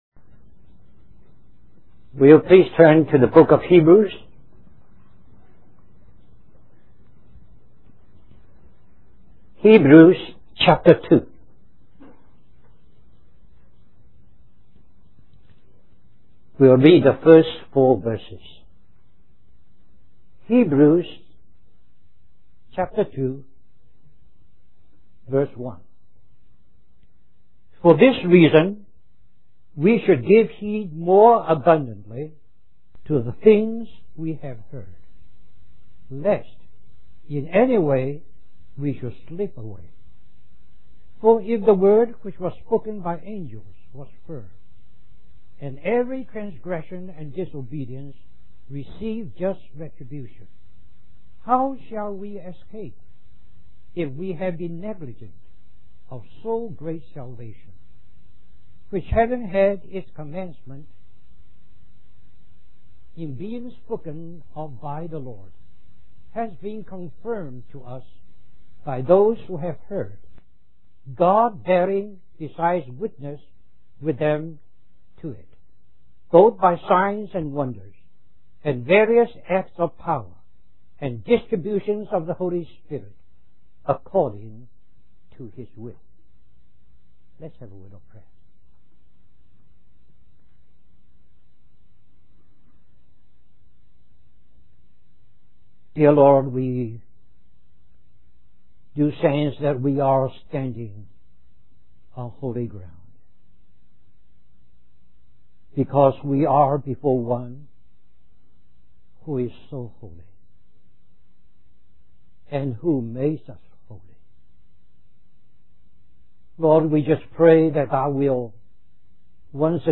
The original audio recording had some very low volume sections that we have attempted to restore with limited success.